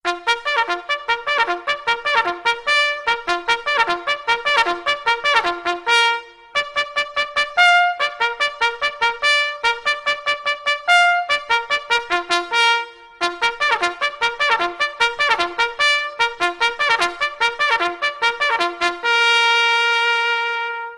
Categoria Sveglia